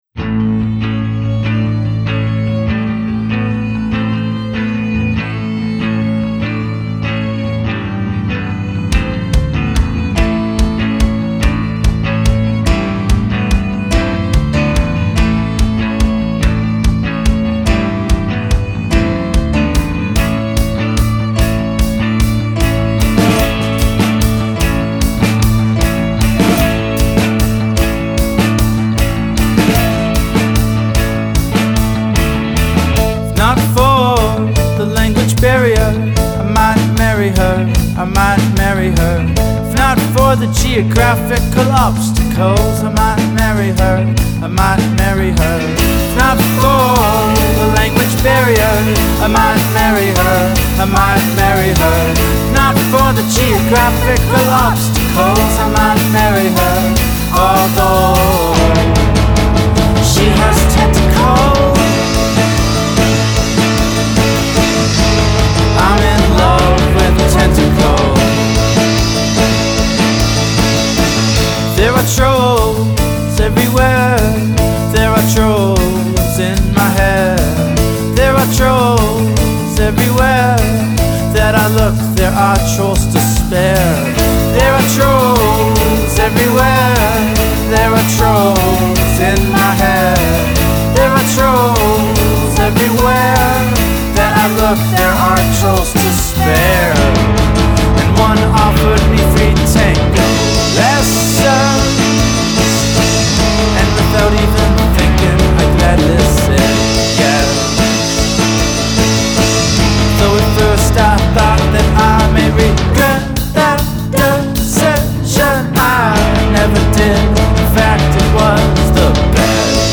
especially the quirky love song